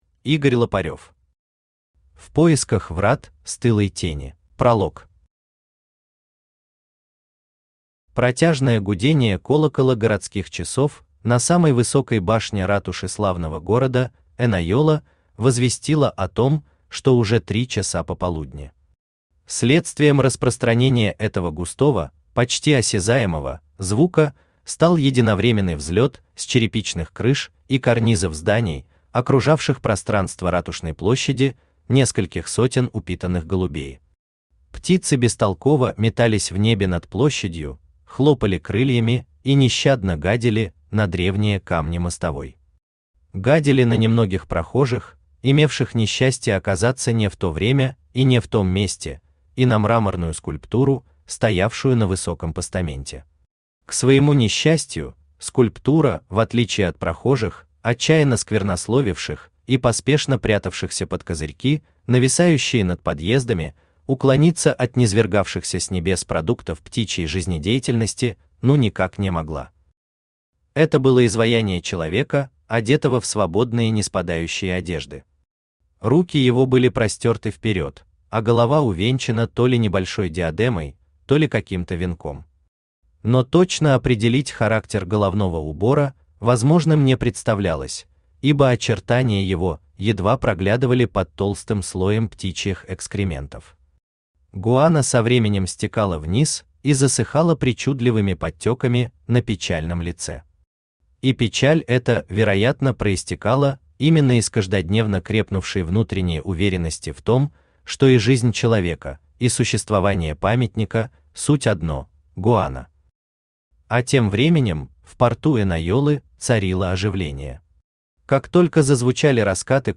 Аудиокнига В поисках врат Стылой Тени | Библиотека аудиокниг
Aудиокнига В поисках врат Стылой Тени Автор Игорь Викторович Лопарев Читает аудиокнигу Авточтец ЛитРес.